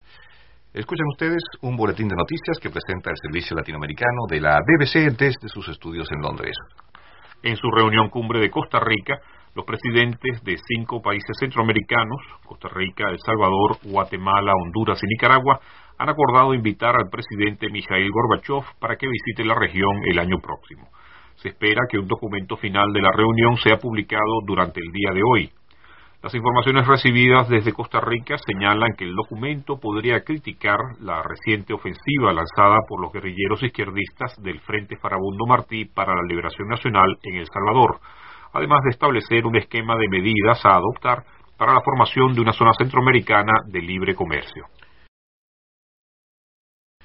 Para terminar, escuche de nuevo la nota simple tal como la presentaron los reporteros de la BBC de Londres.